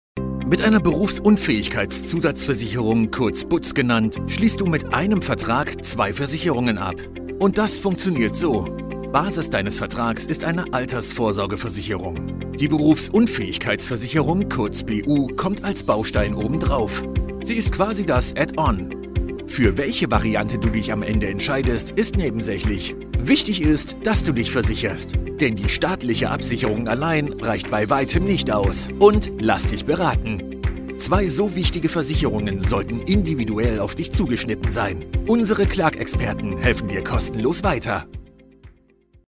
A modern, warm and dynamic voice equally at home in his native German or english
Commercial, Bright, Upbeat, Conversational